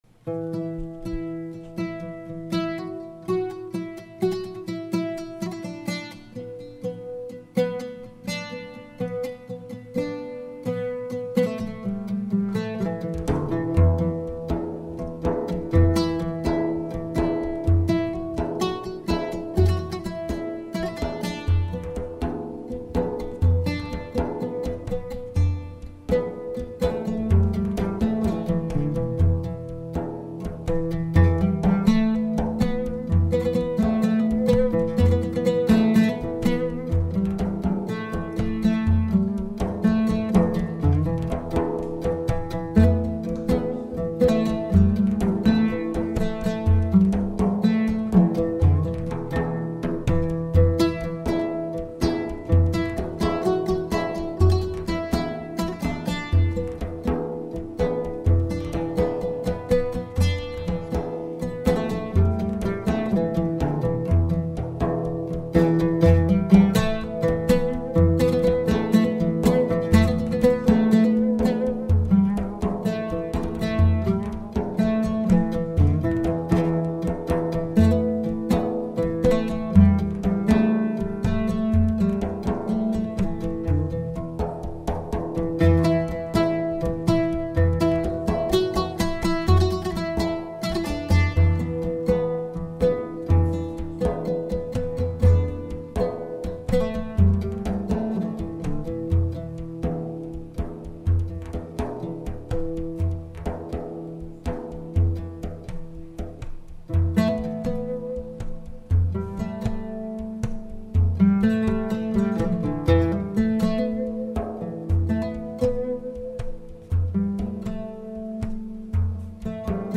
Live at the Empire Dine & Dance, Portland ME
oud
tar